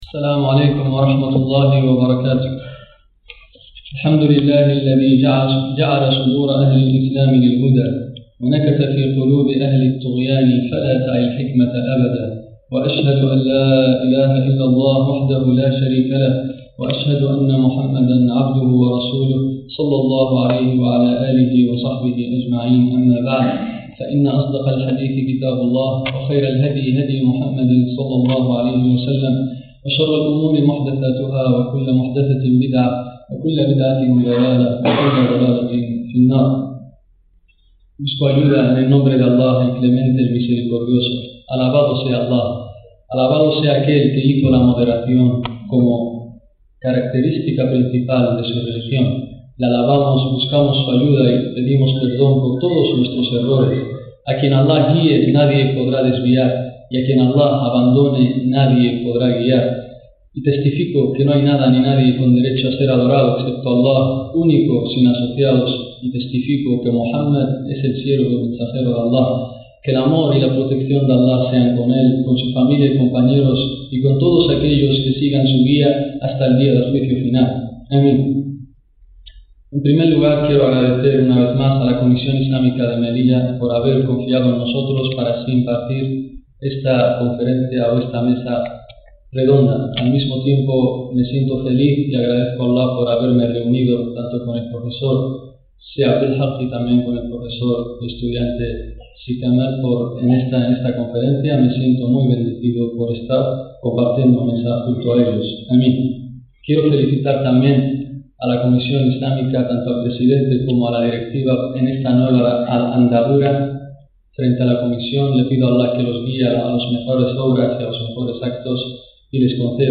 Conferencia impartida en la UNED (Universidad Nacional de Educación a Distancia) en la que se advie